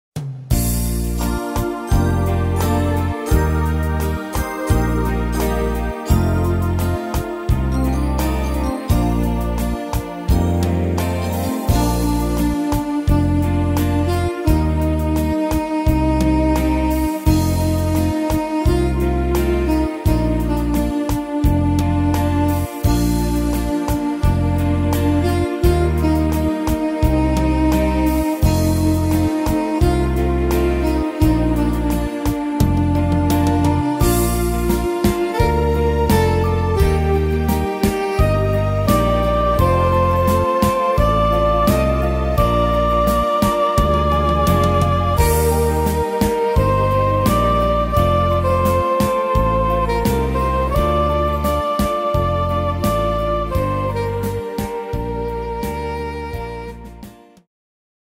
Tempo: 86 / Tonart: Bb-Dur